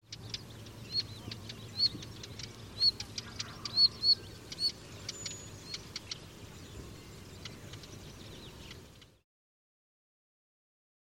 rougequeue-de-moussier.mp3